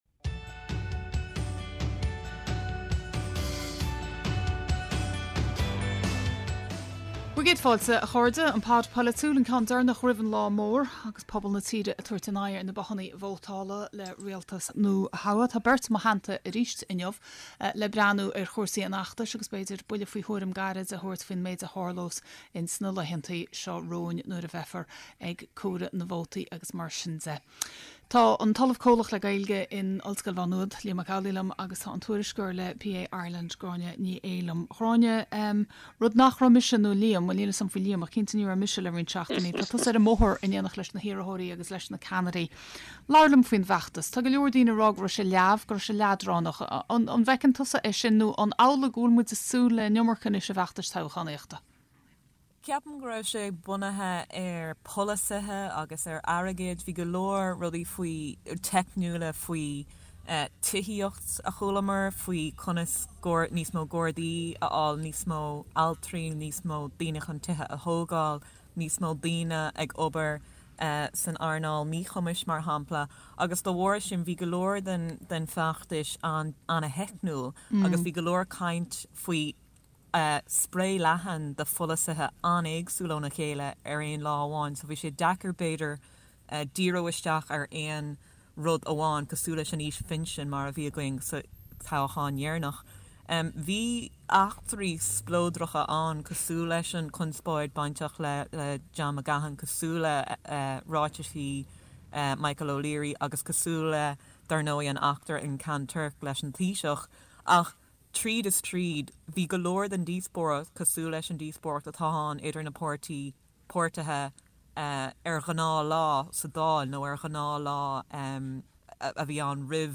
beidh aíonna agus tráchtairí léi sa stiúideo chun súil a chaitheamh ar an méid atá á rá.